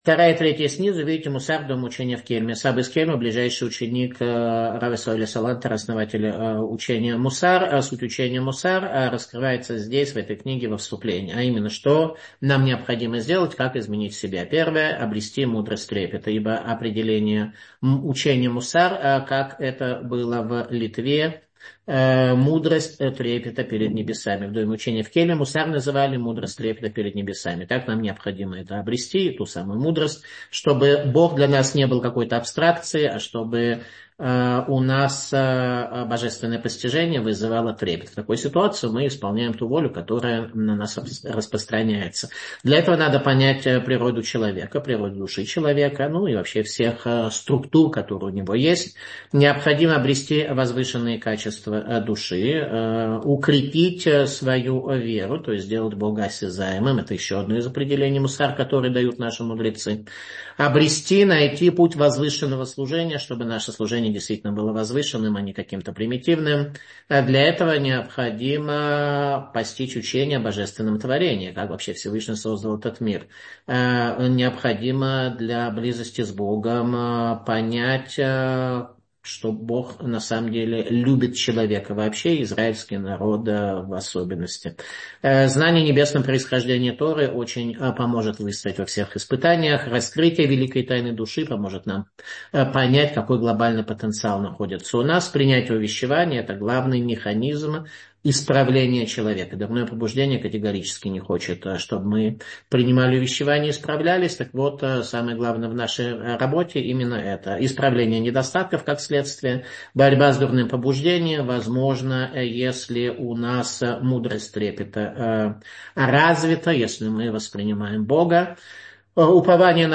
Саба из Кельма — слушать лекции раввинов онлайн | Еврейские аудиоуроки по теме «Мировоззрение» на Толдот.ру